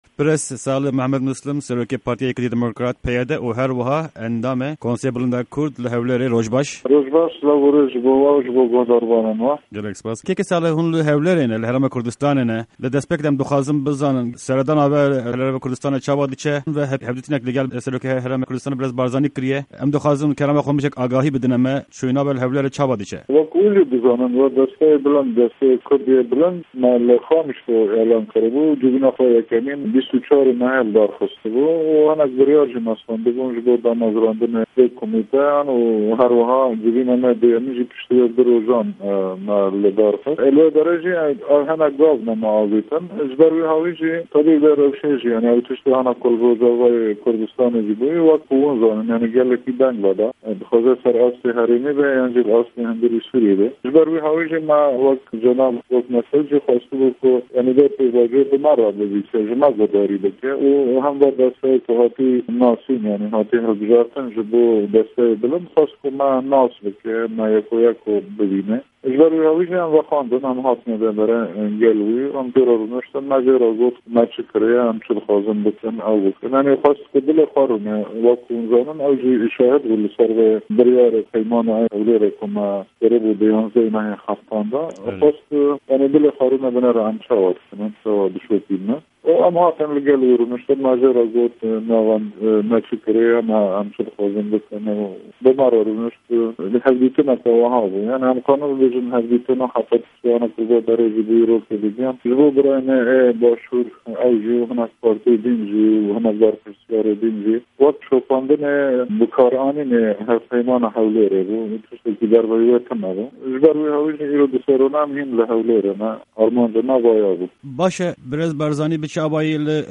Hevpeyvîn_SMM